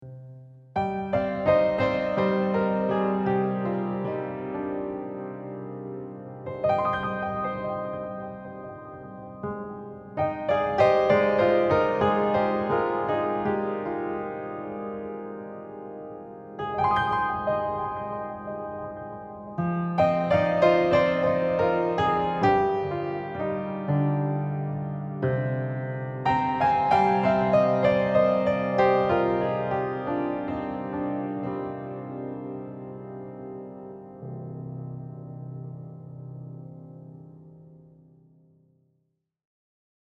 Luminous-Piano-Water.mp3